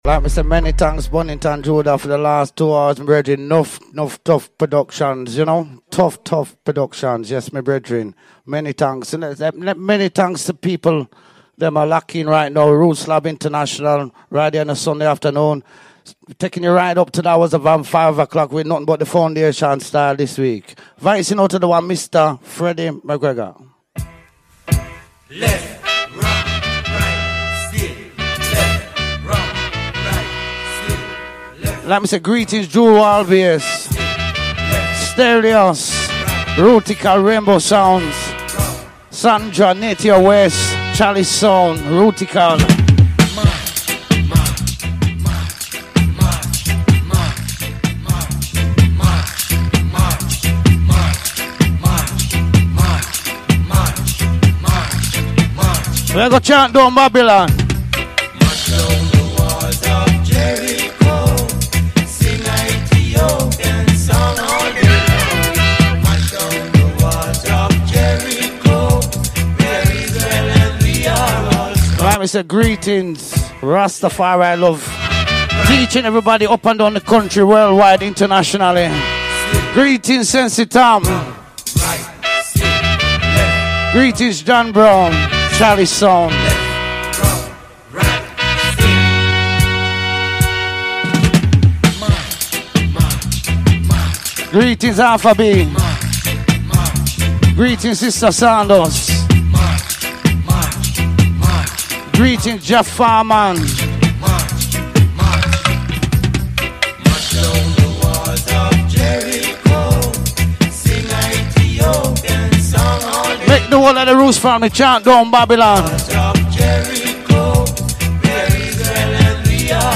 FOR THE BEST IN ROOTS & CULTURE / DUB / STEPPAS VIBES